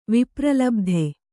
♪ vipralabdhe